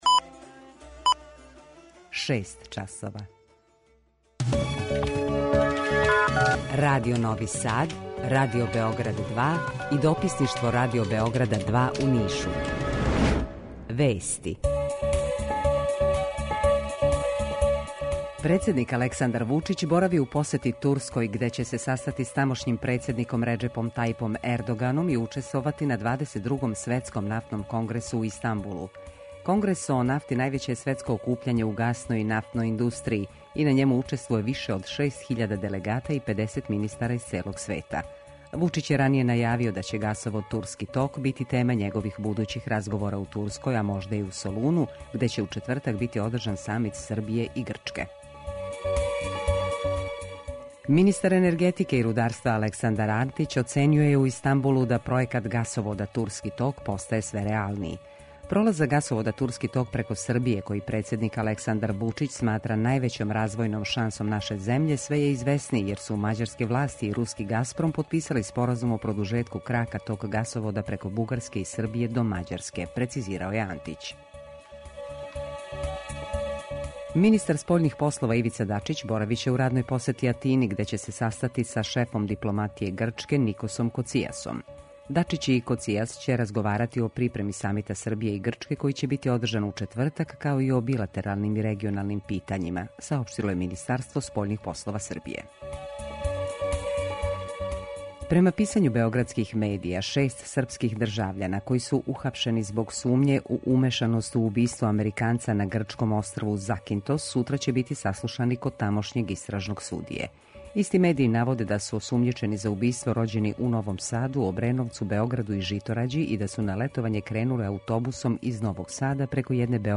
Jутарњи програм заједнички реализују Радио Београд 2, Радио Нови Сад и дописништво Радио Београда из Ниша.
У два сата биће и добре музике, другачије у односу на остале радио-станице.